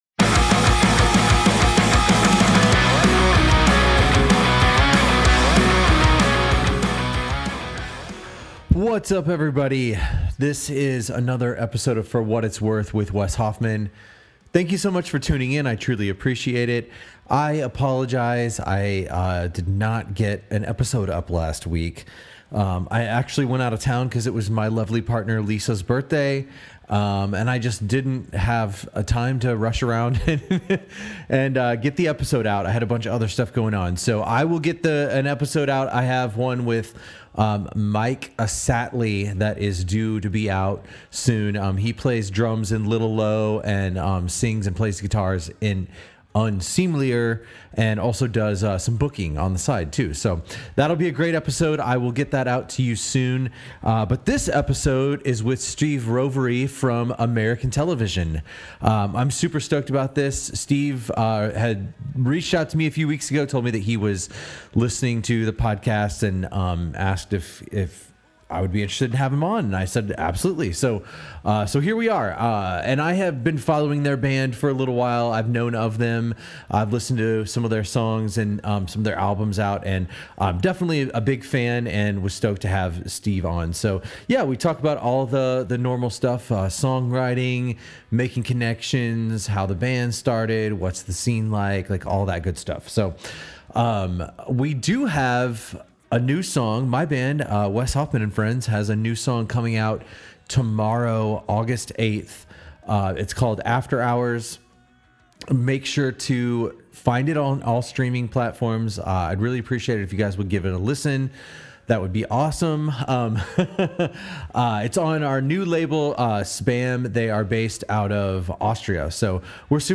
We have a great conversation about touring, songwriting, booking, and much more!